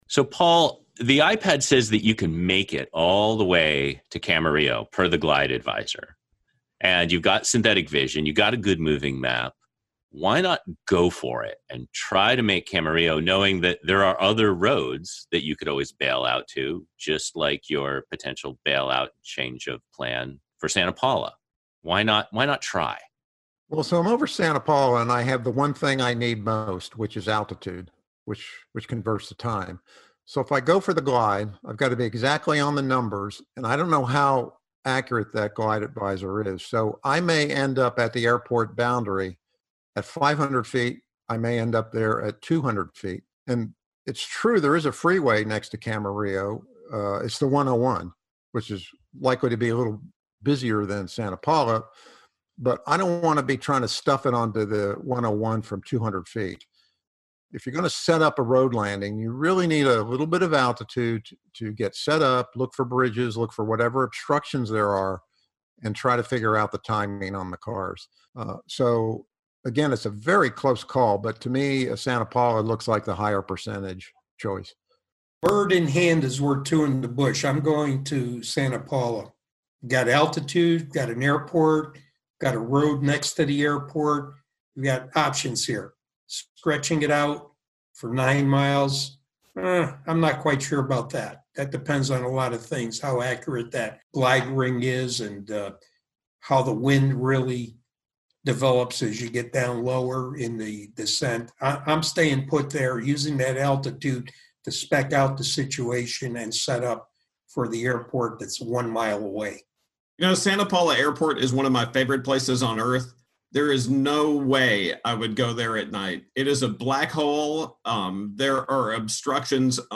gliding_dark_roundtable.mp3